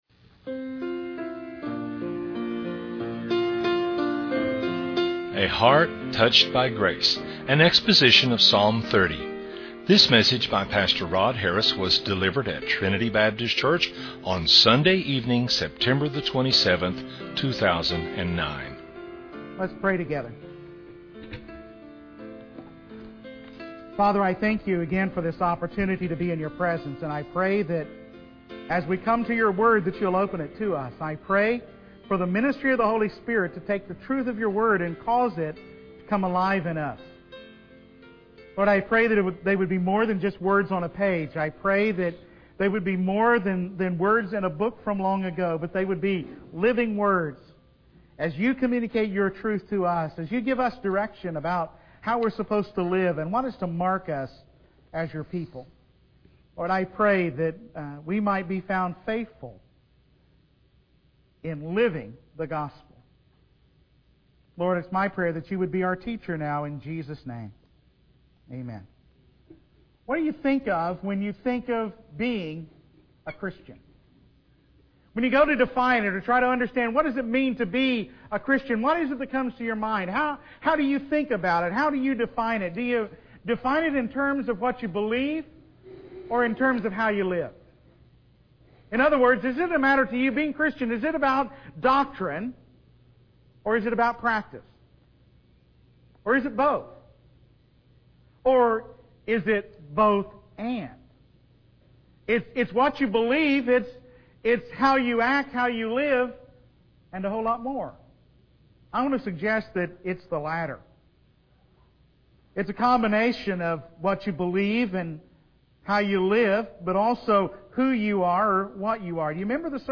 An exposition of Psalm 30.